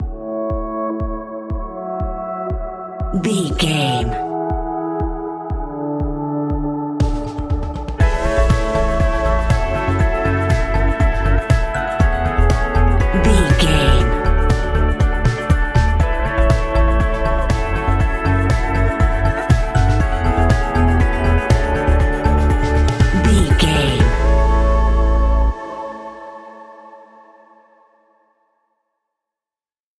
Ionian/Major
energetic
uplifting
bass guitar
electric guitar
synthesiser
percussion
sleigh bells
drums